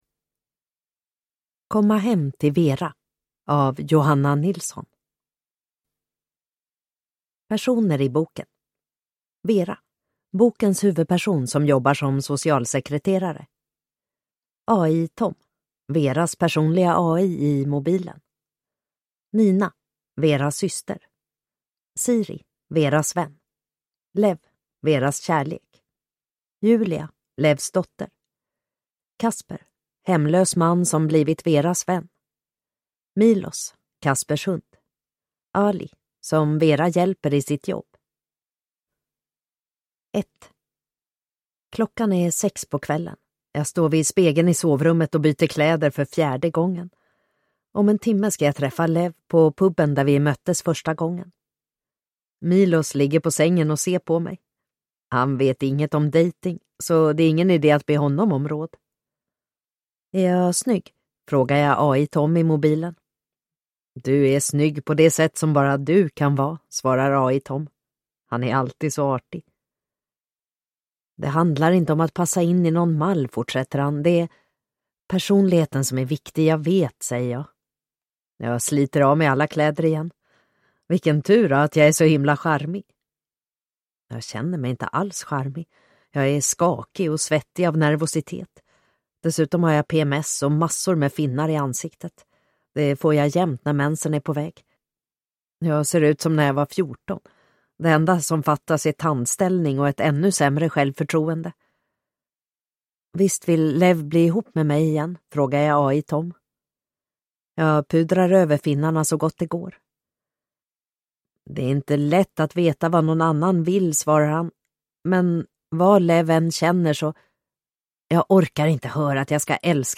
Komma hem till Vera – Ljudbok